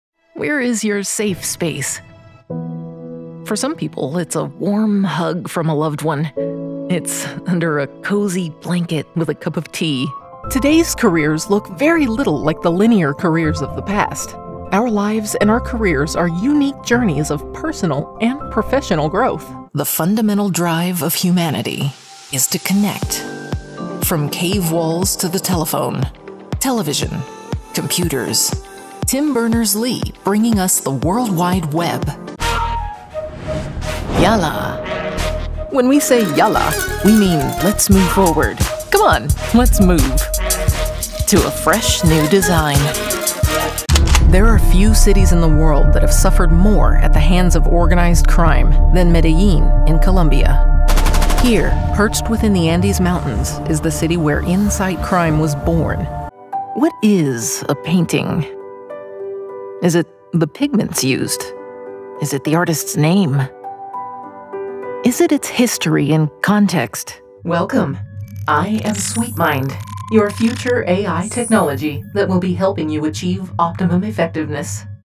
Confident, approachable, sincerity that builds connection.
Narration, warm, documentary, quirky, human
General American, American West Coast
Middle Aged